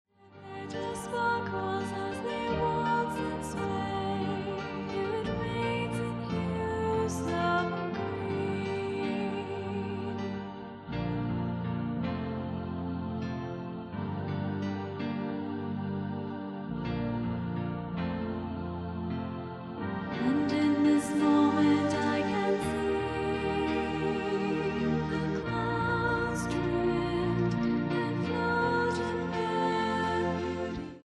Her style ranges between New Age and Electronic.
This CD is a live recording.